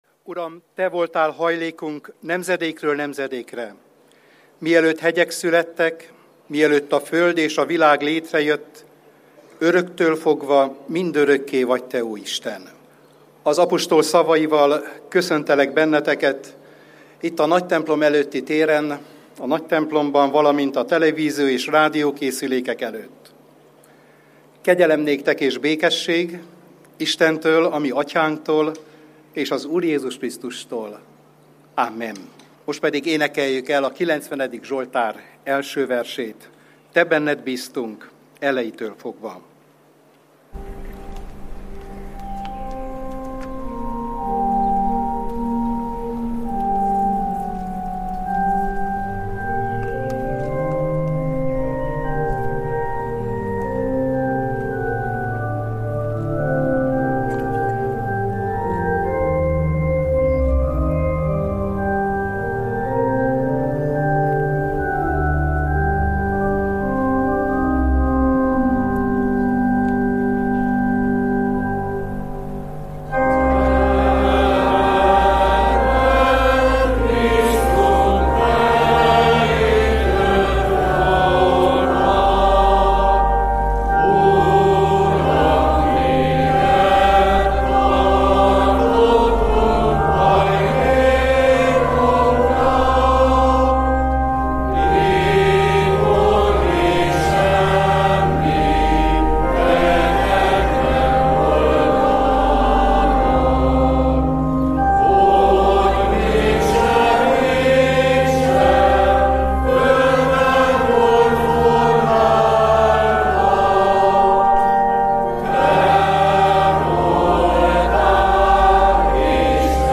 Istentiszteleti közösség a rádió hullámhosszán. Az Európa Rádió hitéleti műsora minden vasárnap és a református egyház ünnepnapjain.